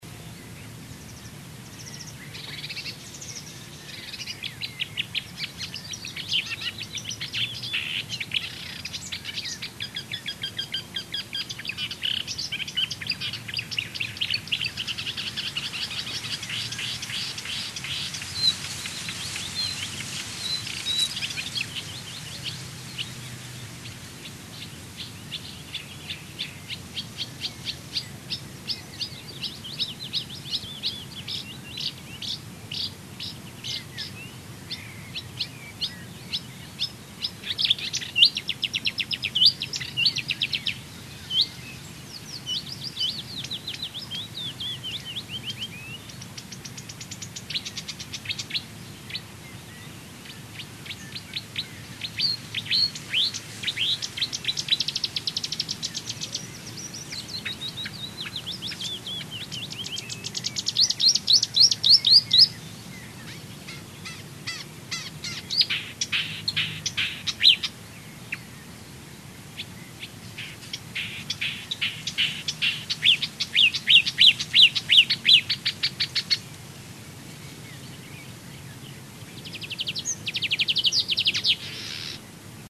It is notable for incorporating striking imitations of a wide variety of other birds into its song.
Song
Each male Marsh Warbler incorporates imitations of a wide range of other birds into its song.